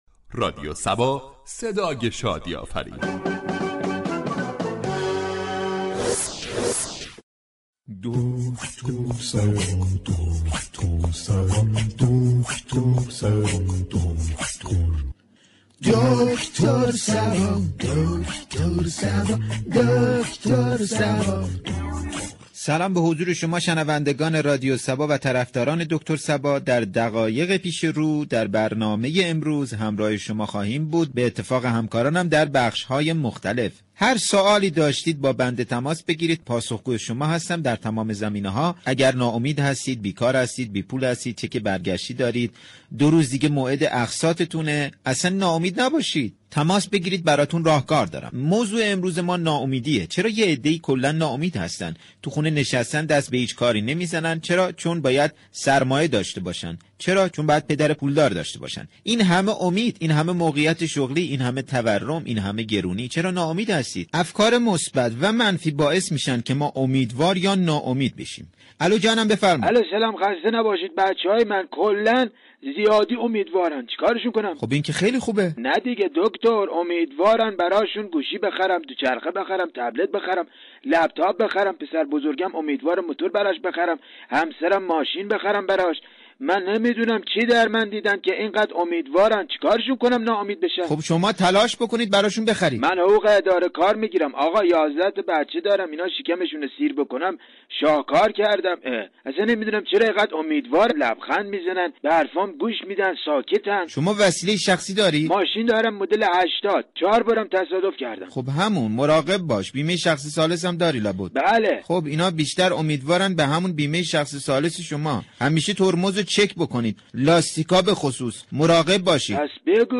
برنامه طنز "دكتر صبا " با بیان مسایل اجتماعی و فرهنگی با نگاهی طنز برای مخاطبان نسخه شادی و لبخند می پیچید.